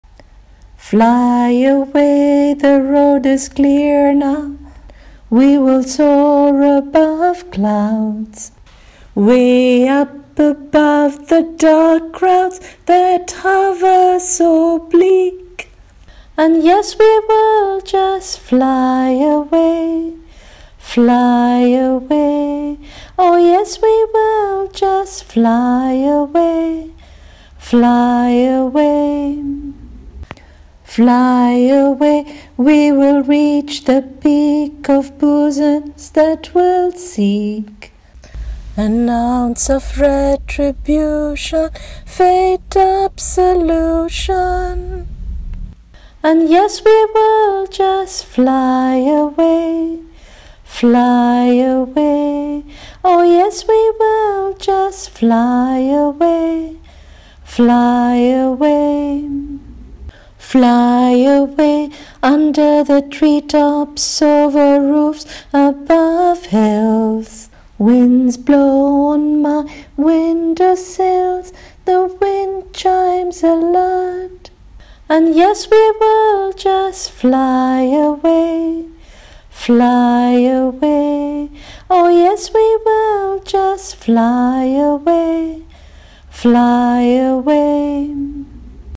Singing of the poem:
fly-away-song.mp3